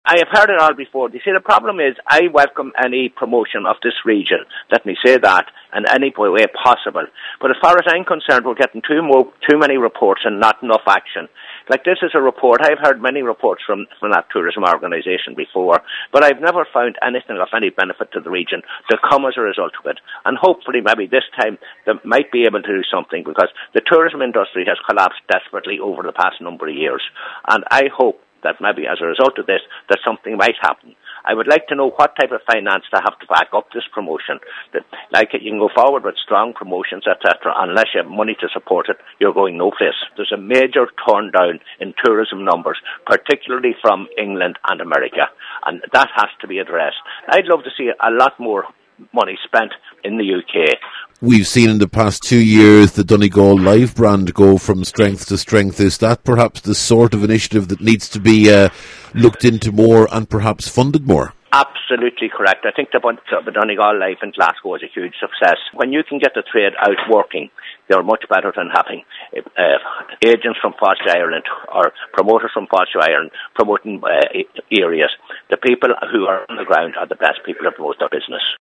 However, Councillor Sean Mc Eniff says this report says nothing that isn’t already known……